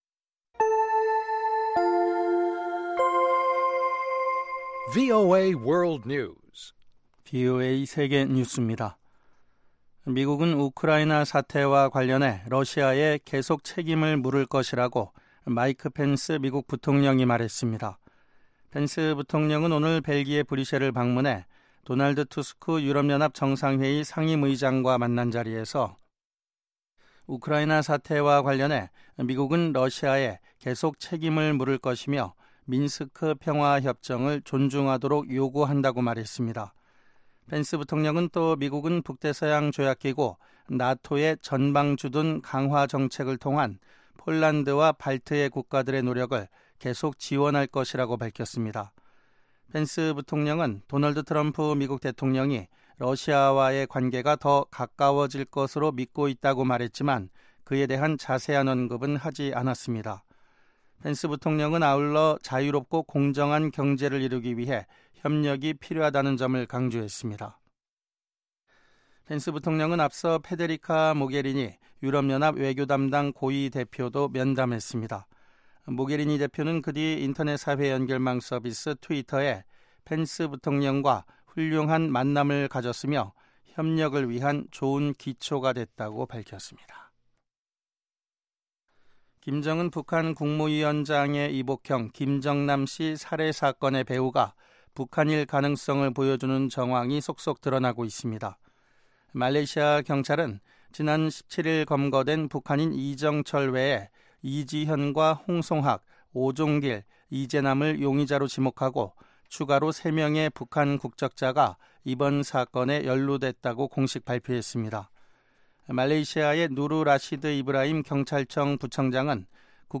VOA 한국어 방송의 간판 뉴스 프로그램 '뉴스 투데이' 3부입니다. 한반도 시간 매일 오후 11:00 부터 자정 까지, 평양시 오후 10:30 부터 11:30 까지 방송됩니다.